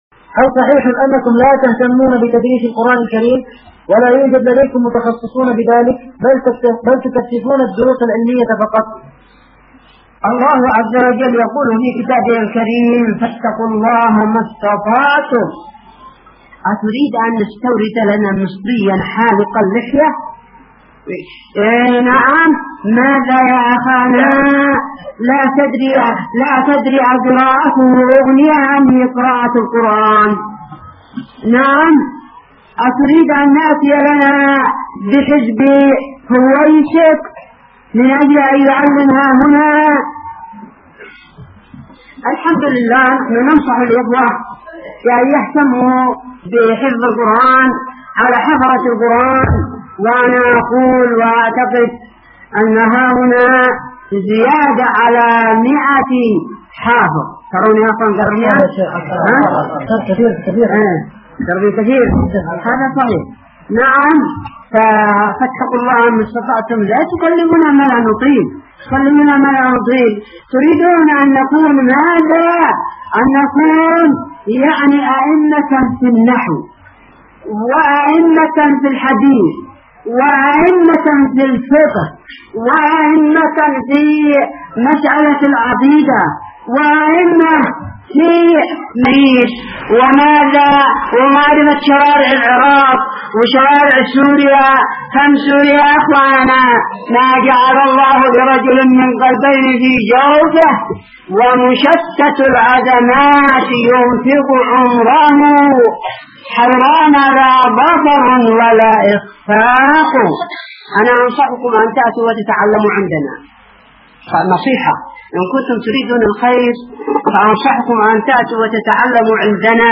فتاوى